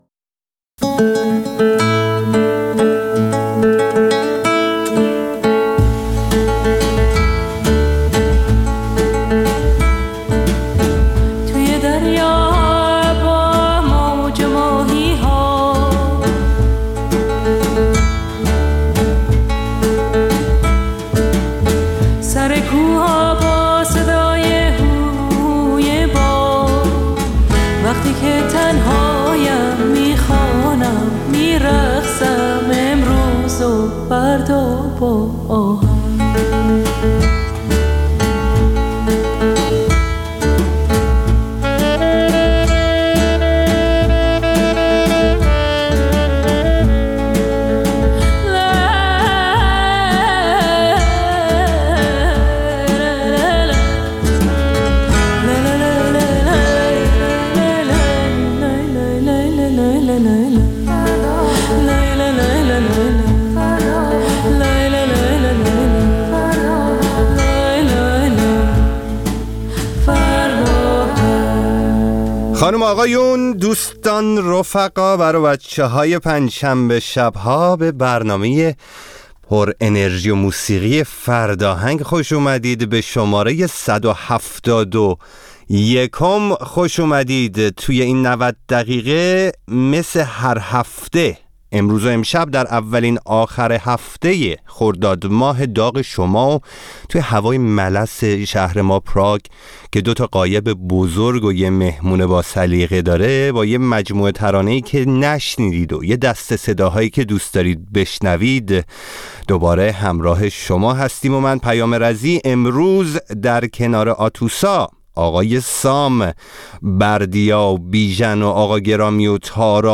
برنامه زنده بخش موسیقی رادیو فردا.